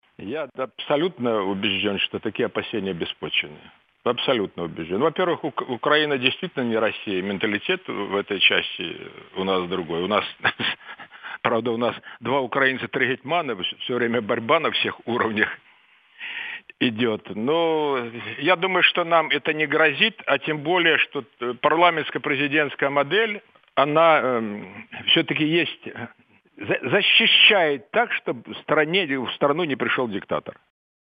Из интервью Леонида Кучмы РадиоАзадлыг